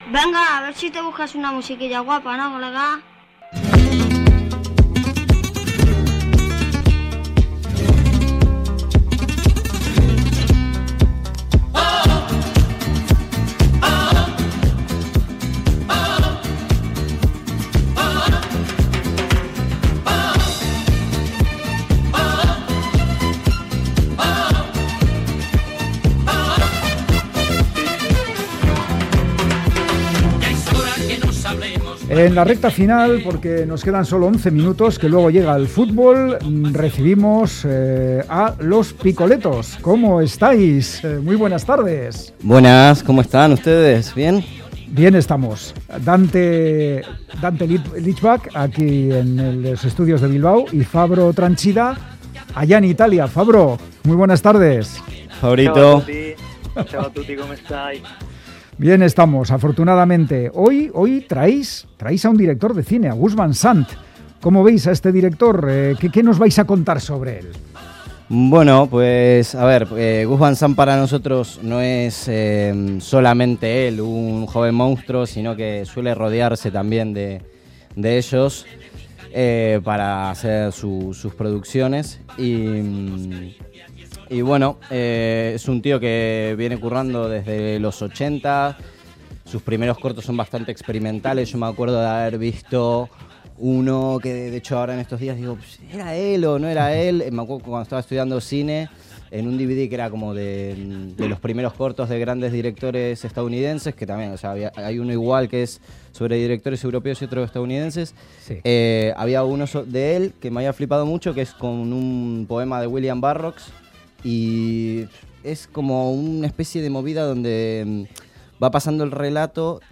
Audio: Los Picoletos comentan parte de la filmografía del director estadounidense acompañada de la música de los filmes